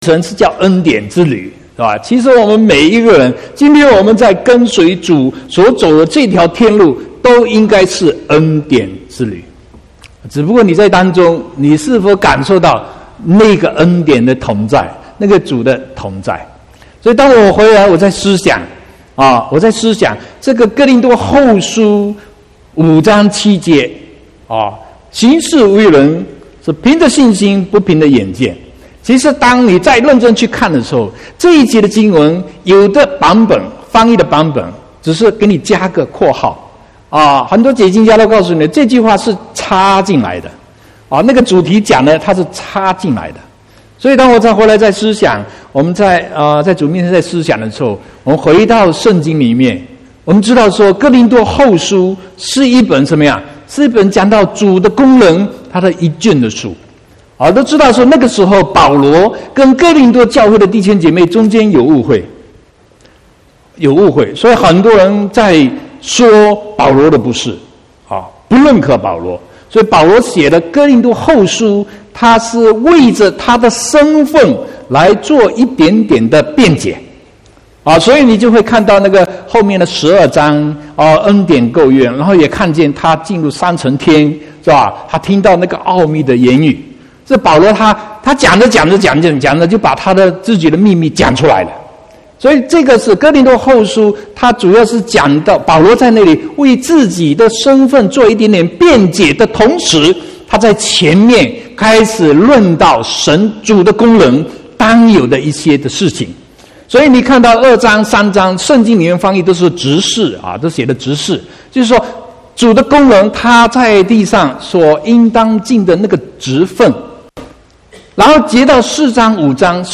21/10/2018 國語堂講道